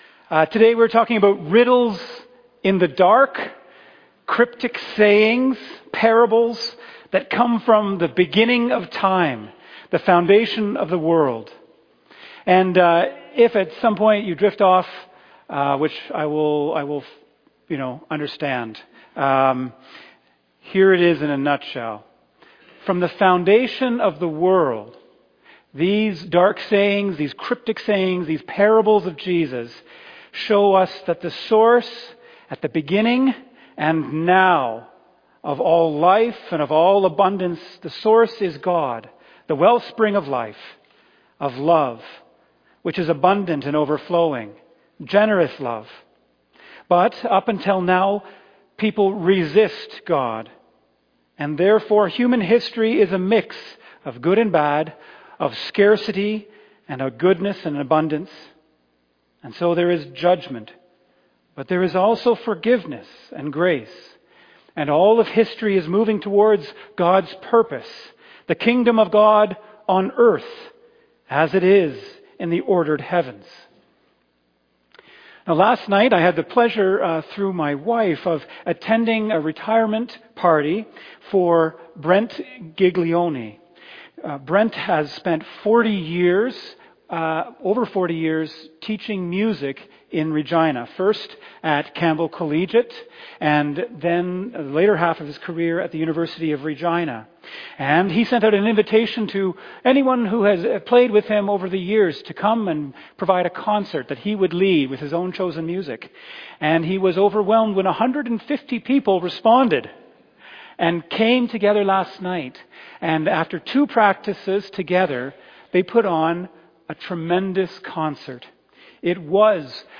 2025 Sermon April 19, 2026
JOIN our Worship Service: 11:00 am